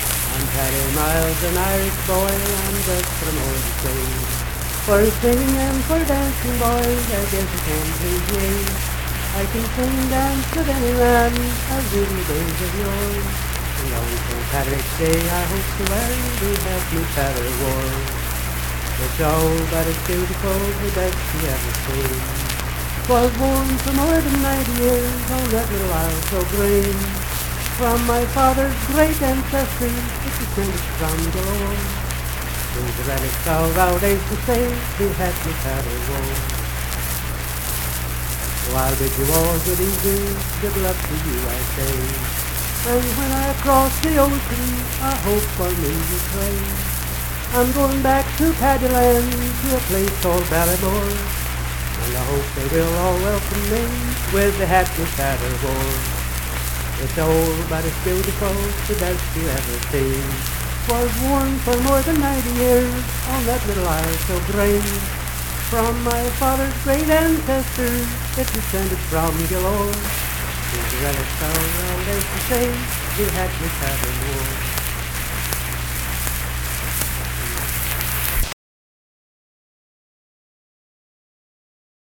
Unaccompanied vocal music
Verse-refrain 3(8).
Ethnic Songs
Voice (sung)
Nicholas County (W. Va.), Richwood (W. Va.)